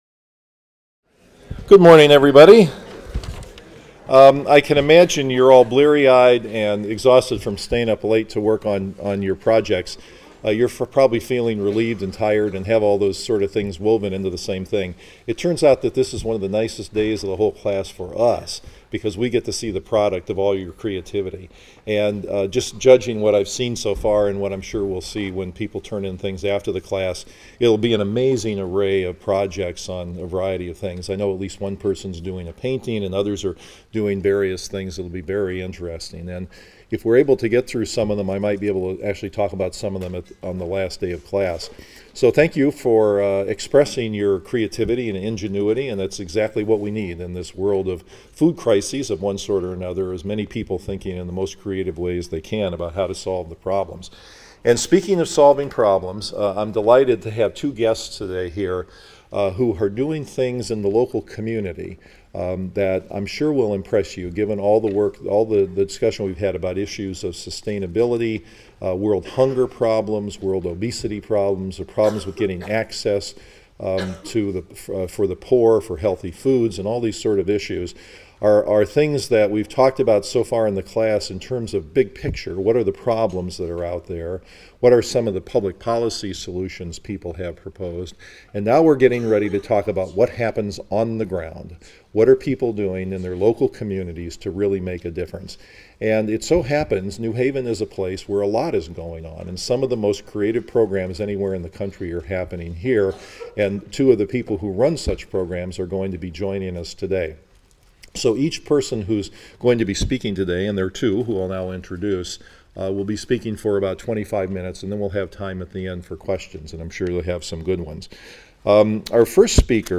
PSYC 123 - Lecture 22 - Sustainability and Health Food Access